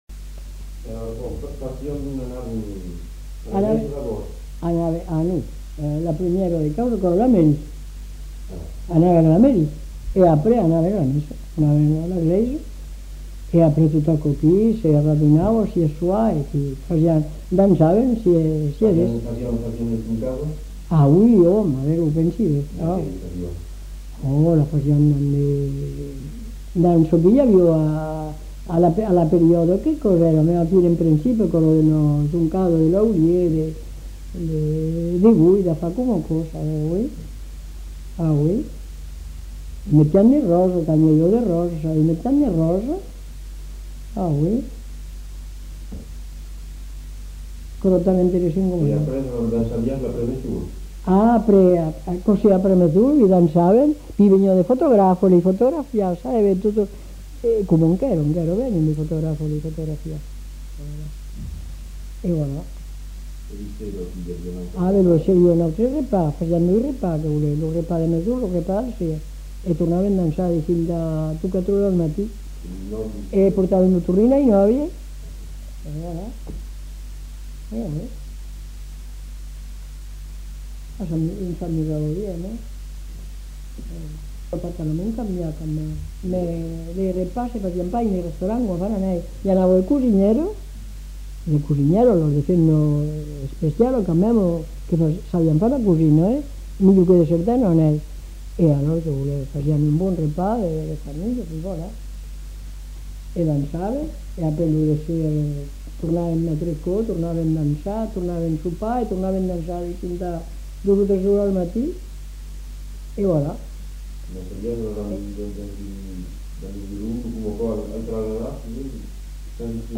Aire culturelle : Haut-Agenais
Lieu : Cancon
Genre : témoignage thématique